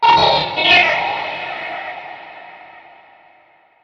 Чужой: фантастический звуковой эффект
Тут вы можете прослушать онлайн и скачать бесплатно аудио запись из категории «Фантастика, Sci-fi».